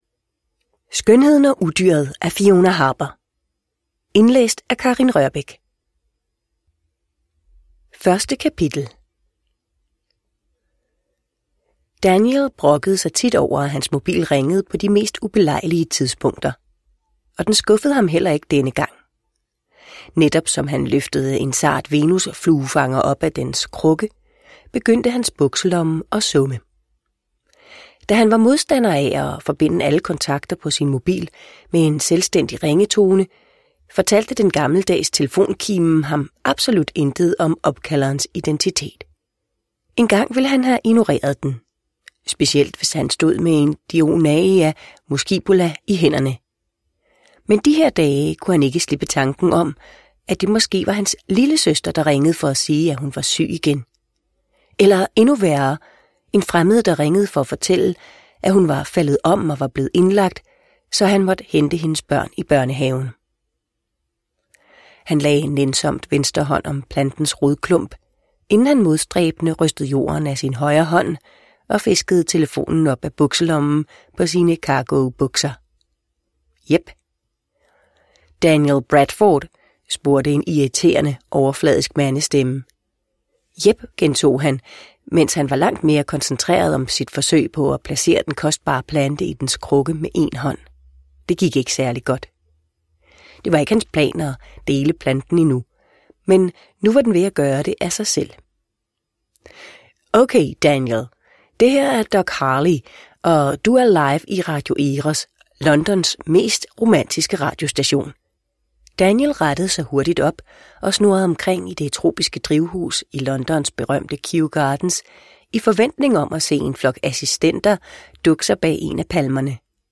Skønheden og Udyret – Ljudbok – Laddas ner